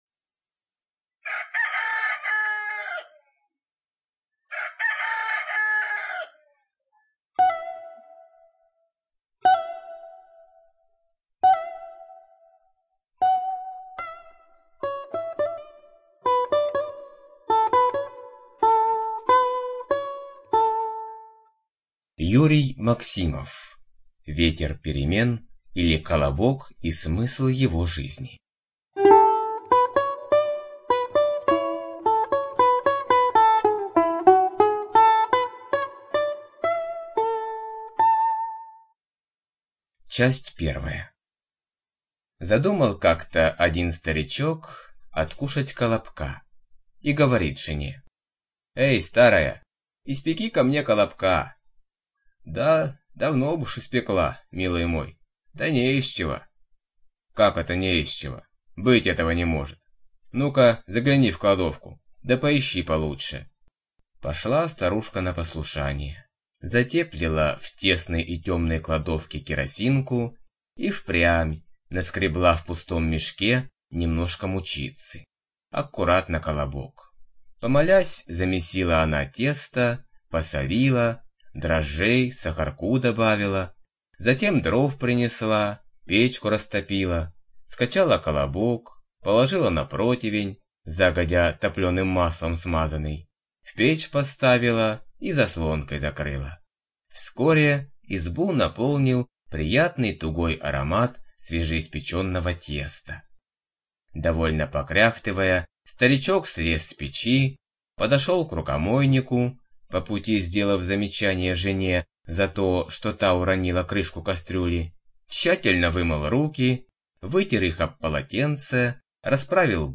ЖанрЮмор и сатира